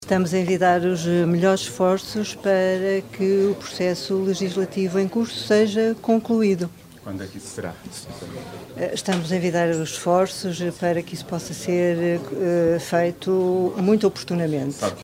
Declarações à margem da sessão de assinatura do protocolo para a reabilitação do Posto Territorial da GNR de Macedo de Cavaleiros, que se realizou hoje à tarde no edifício do Centro Cultural.